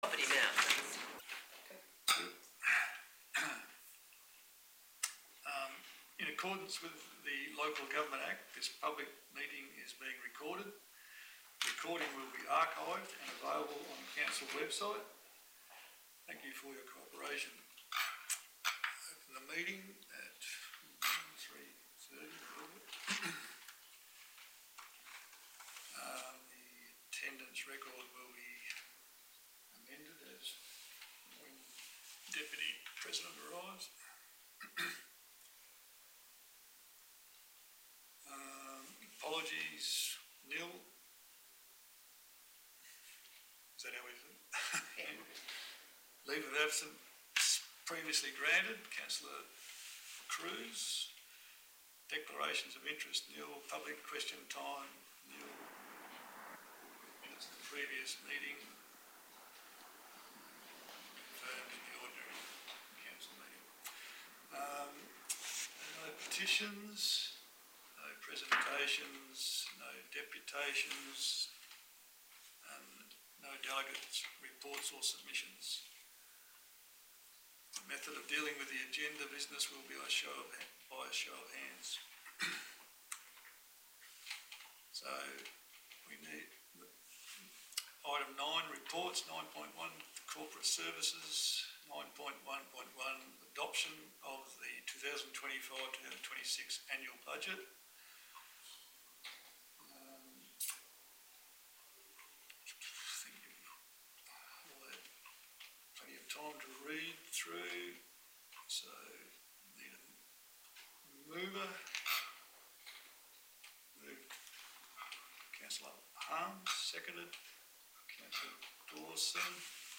Special Council Meeting - 15 July 2025 » Shire of Dalwallinu
A Special Council Meeting of the Shire of Dalwallinu was held on Tuesday 15 July 2025 and commenced at 3:30pm in the Shire of Dalwallinu Council Chambers for the purpose of: Adoption of 2025-2026 Annual Budget. No members of the public were in attendance.